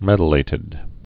(mĕdl-ātĭd)